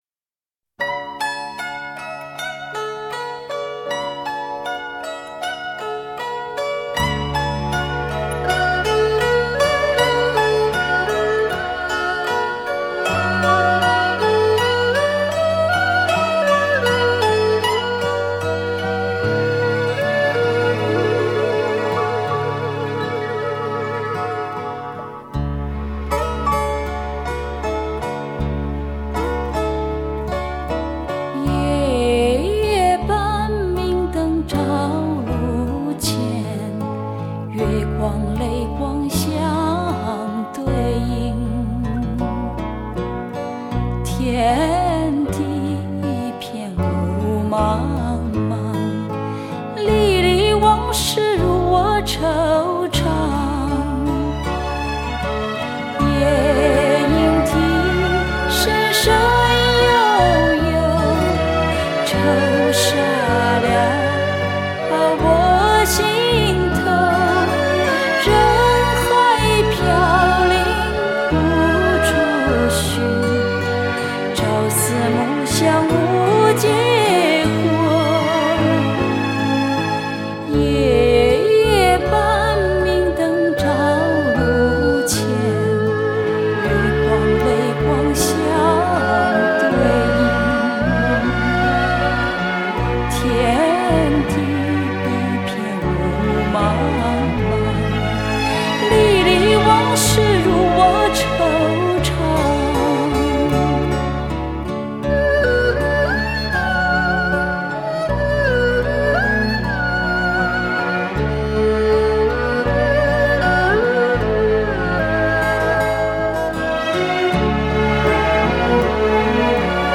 本套全部歌曲皆数码系统重新编制
令音场透明度及层次感大为增加
并使杂讯降为最低